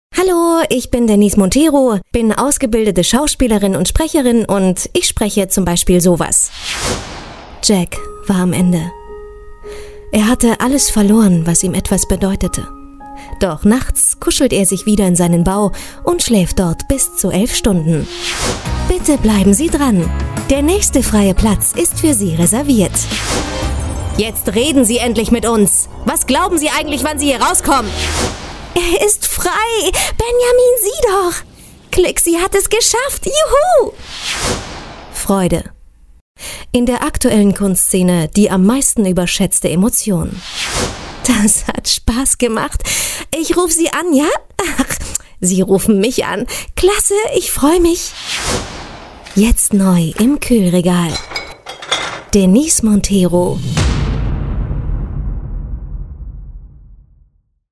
Kein Dialekt
Sprechprobe: eLearning (Muttersprache):